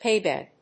アクセント・音節páy・bèd